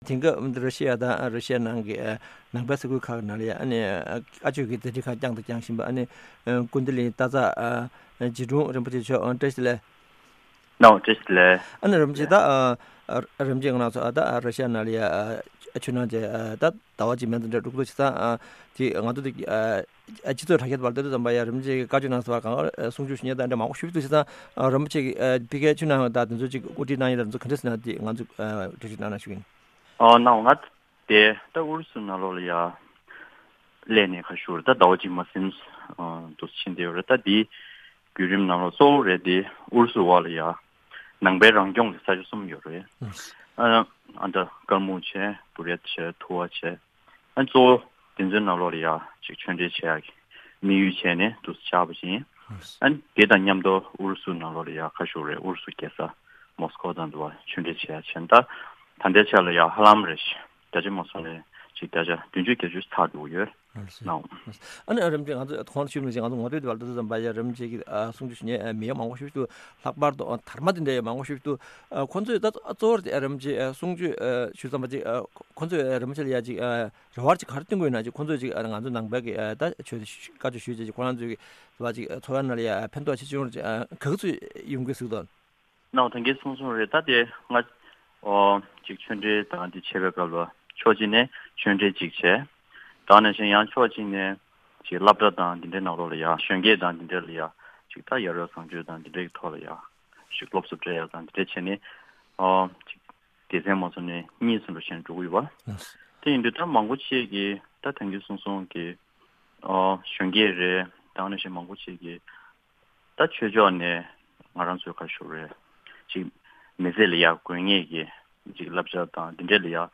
རིན་པོ་ཆེ་མཆོག་ས་ཁུལ་དེའི་གནས་སྟངས་སོགས་ལ་བཅར་འདྲི་ཞུས་པའི་གནས་ཚུལ་ཞིག་གསན་རོགས་གནང་།།